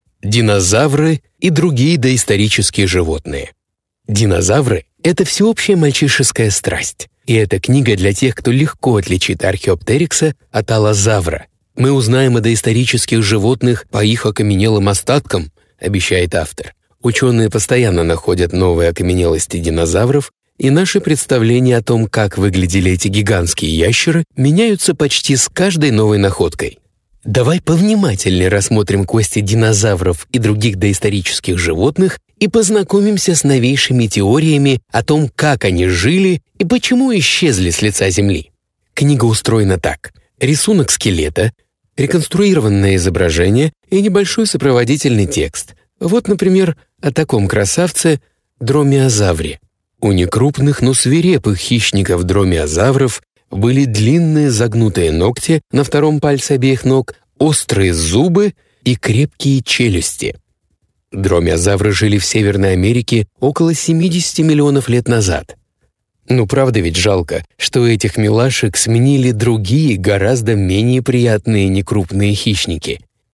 закадровое озвучание и др. 0000:00 NARRATION_2180 00:00 00:00 00:00 30 сек.
Двустенная звукозаписывающая кабина 4”х6” «Whisperroom»;